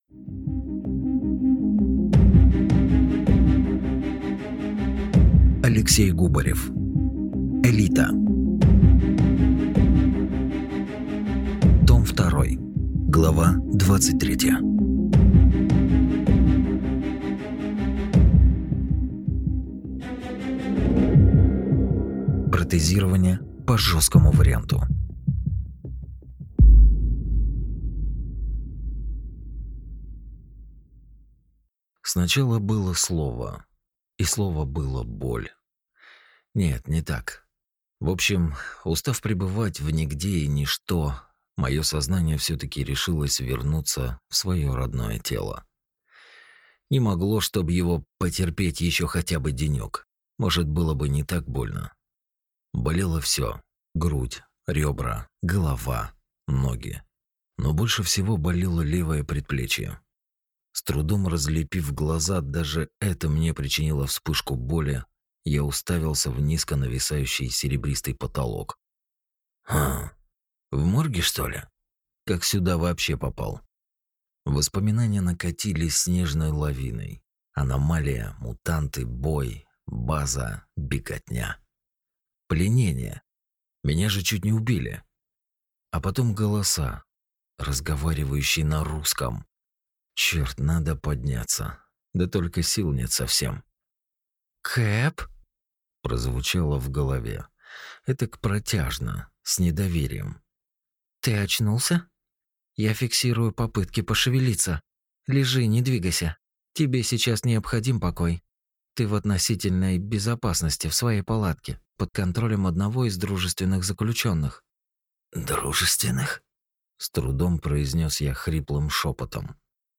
Аудиокнига Элита | Библиотека аудиокниг